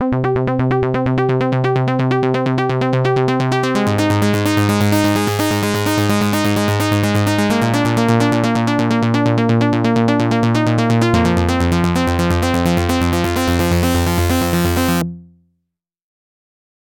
To add some movement I linked the resonance and the cut off to the mod wheel and recorded in some controller data.
The modulation playing back.